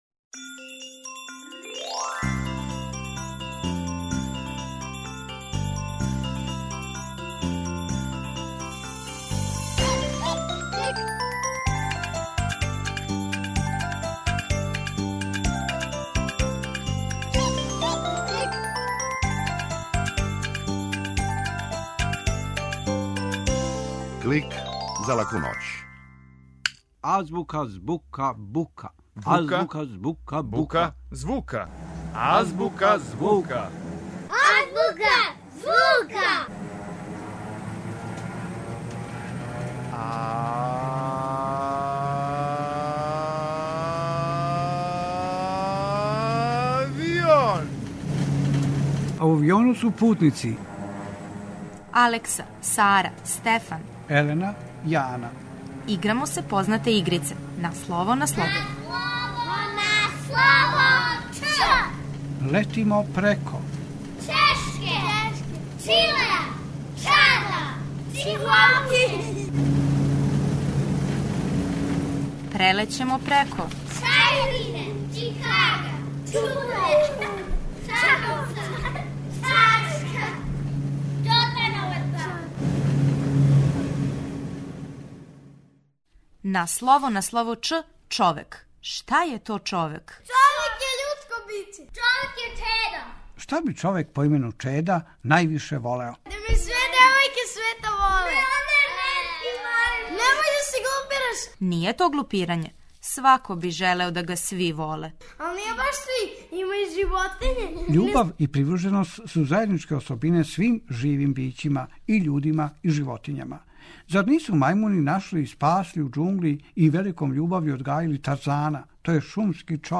Клик је кратка емисија за децу, забавног и едукативног садржаја. Сваке седмице наши најмлађи могу чути причу о деци света, причу из шуме, музичку упознавалицу, митолошки лексикон и азбуку звука. Уколико желите да Клик снимите на CD или рачунар, једном недељно,на овој локацији можете пронаћи компилацију емисија из претходне недеље, које су одвојене кратким паузама.